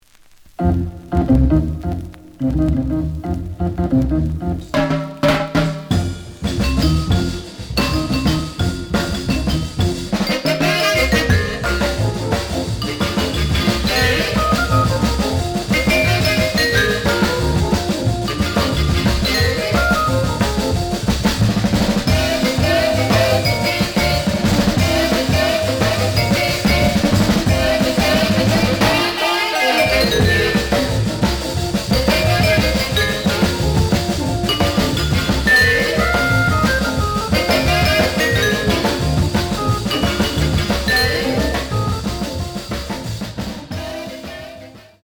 The audio sample is recorded from the actual item.
●Genre: Jazz Other
Slight edge warp.